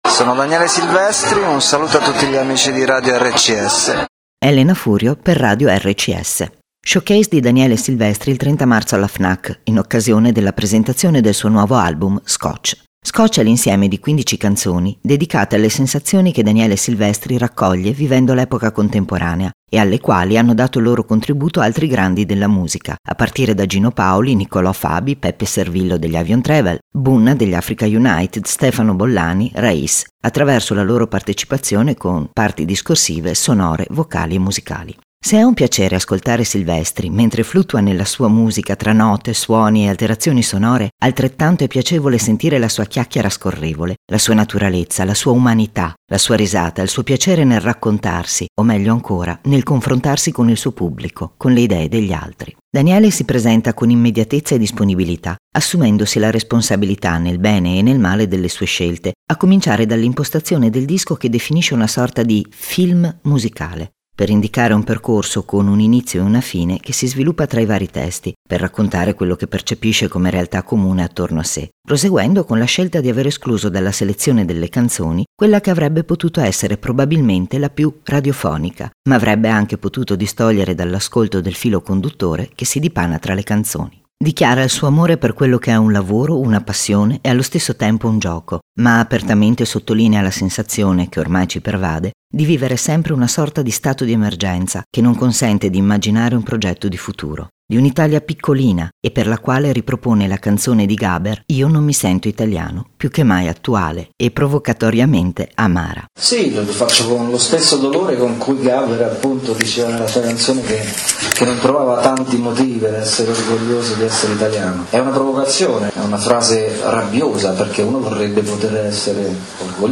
Servizio su Daniele Silvestri incontrato alla Fnac di Verona il 30 Marzo 2011 e andato in onda su Radio RCS, ascoltalo